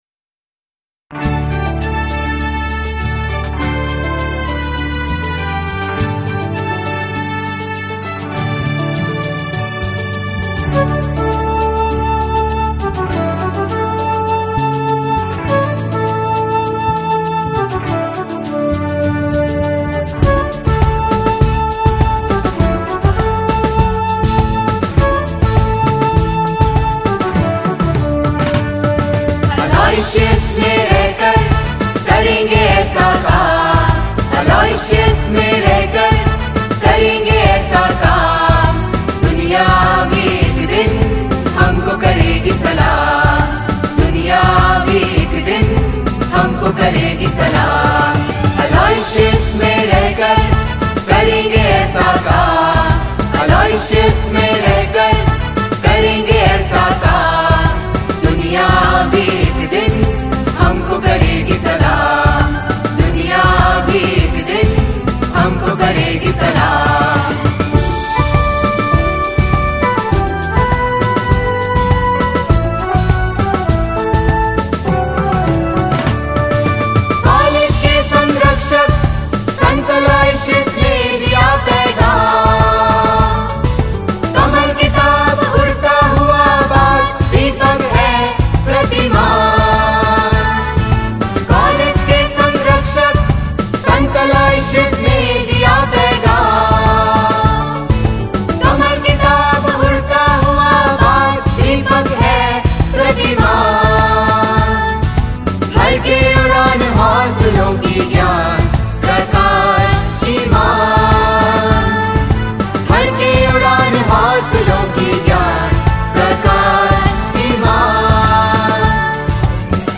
Logo & Anthem – St. Aloysius' College (Autonomous), Jabalpur